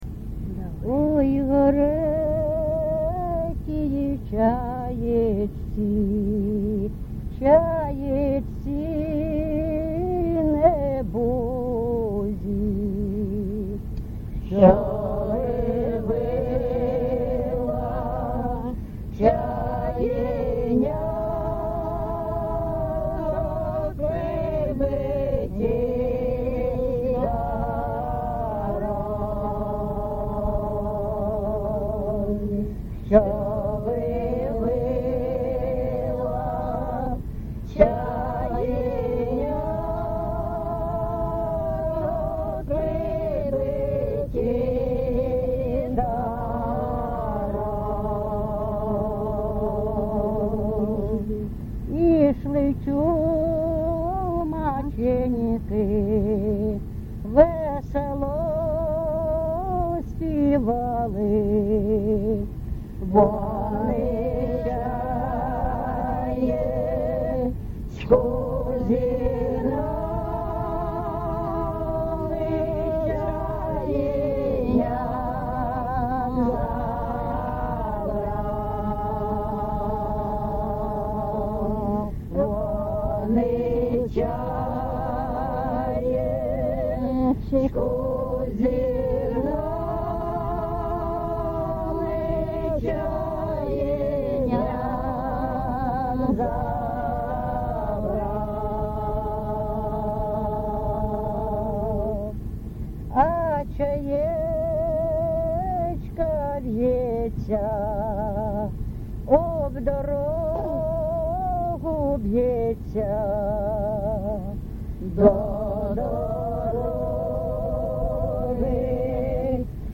ЖанрЧумацькі, Балади
Місце записус. Богородичне, Словʼянський район, Донецька обл., Україна, Слобожанщина